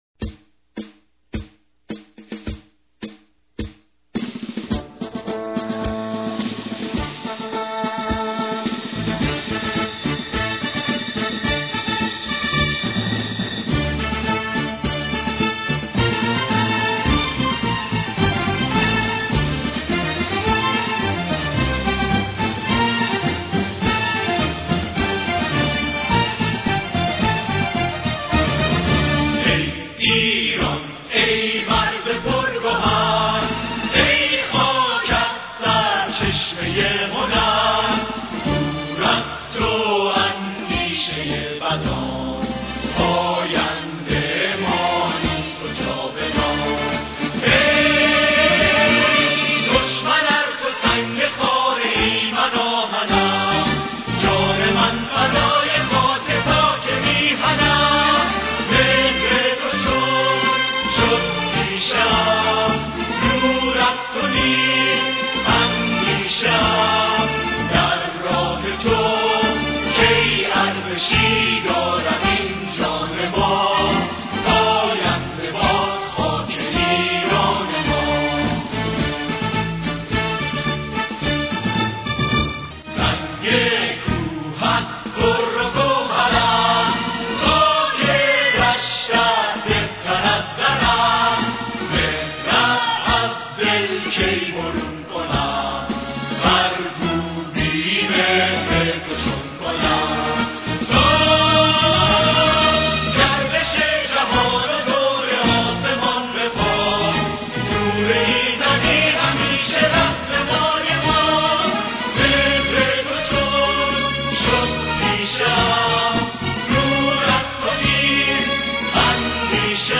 خواننده اصلی: غلامحسین بنان
بازخوانی انقلابی و جنگی: دهه ۶۰ با اجرای گروه کر صداوسیما و گروه‌های سرود مدارس
ویژگی‌ها: سرودی ملی‌گرایانه با لحنی حماسی و زبان ادبی قدرتمند
در مدارس، گروه‌های سرود، و حتی صداوسیما، نسخه‌هایی بدون ساز از این اثر پخش می‌شد که فضای پرغرور و وطن‌دوستانه‌ای ایجاد می‌کرد.